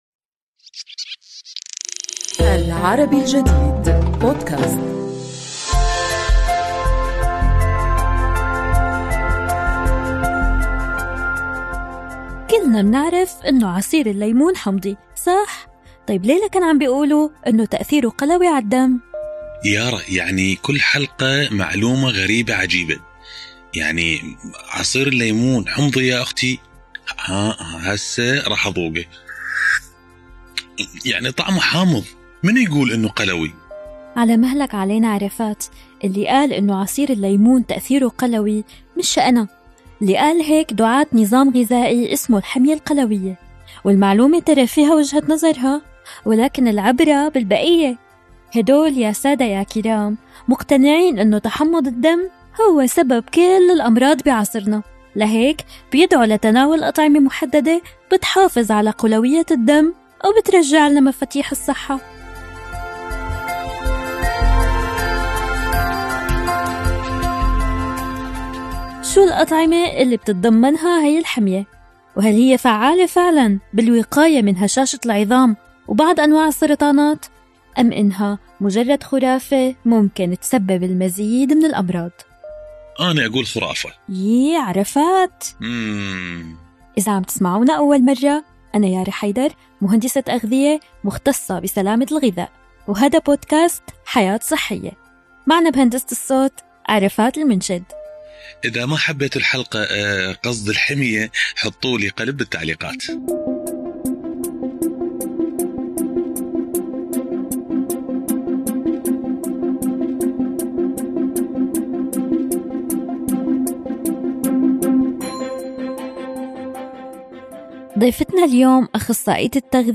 نستضيف أخصائية التغذية العلاجية